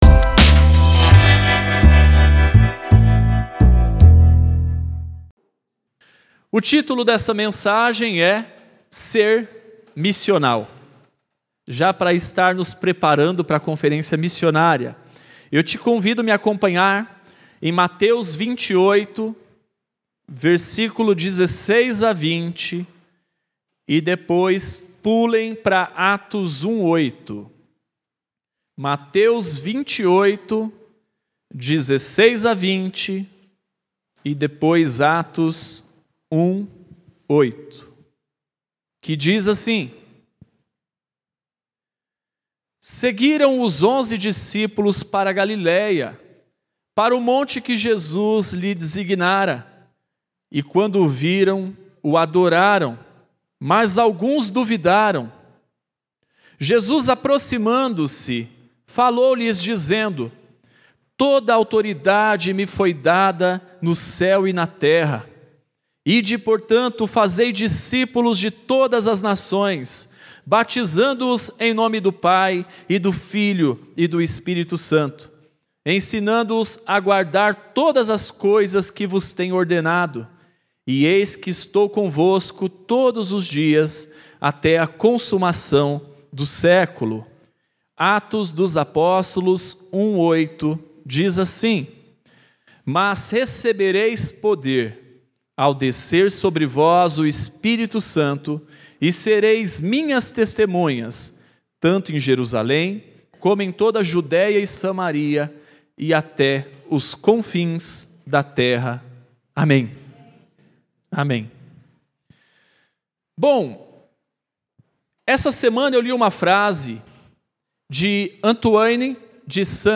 Introdução a nossa Conferência Missionária de 2022.